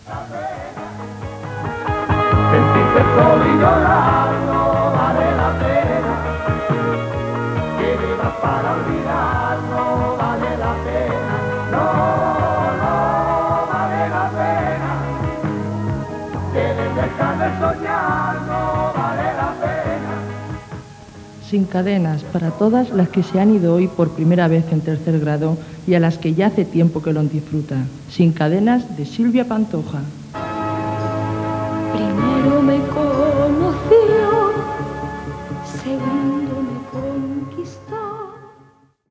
Tema musical